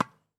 Ball Hit Volley.wav